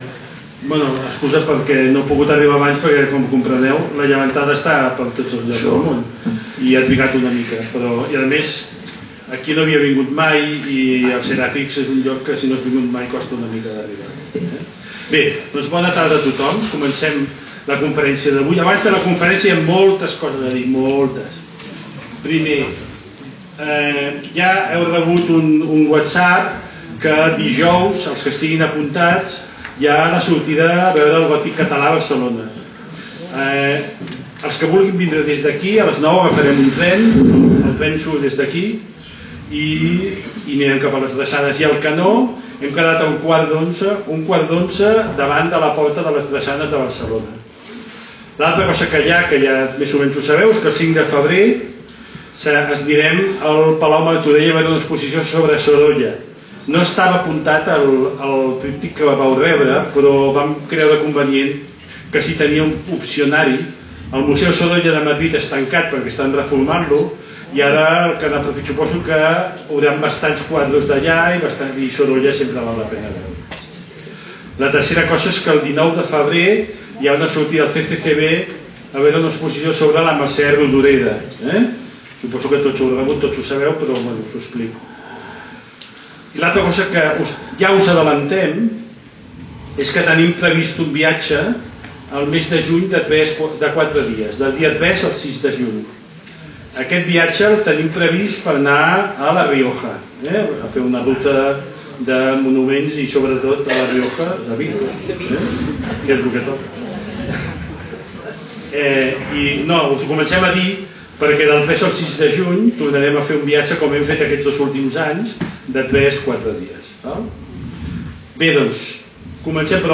Lloc: Casal de Joventut Seràfica
Conferències